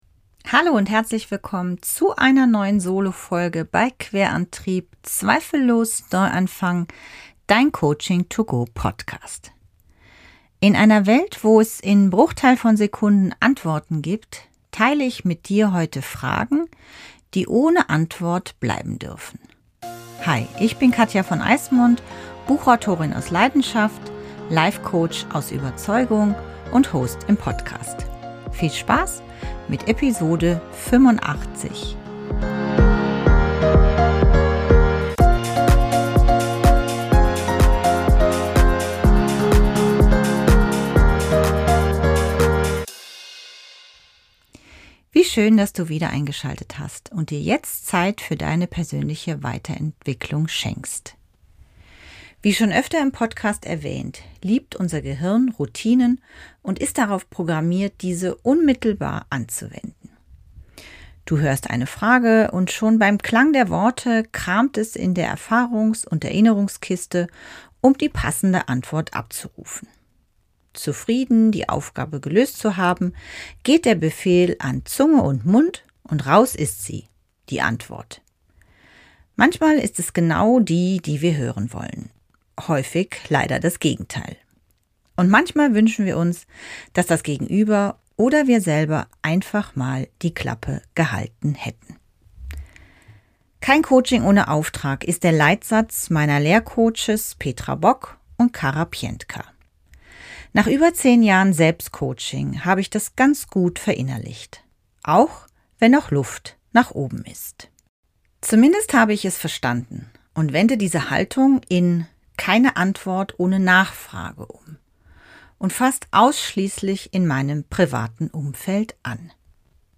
Selbst-Coaching